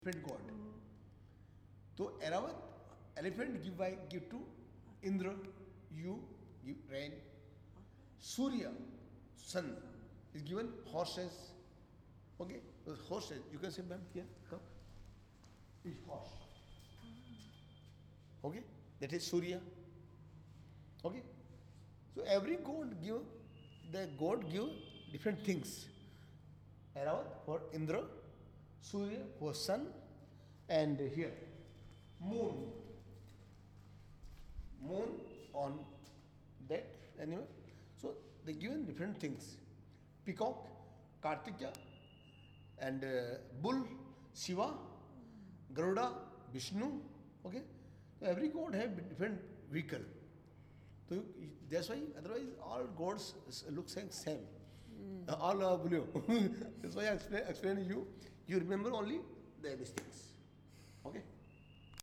guide_haveli.mp3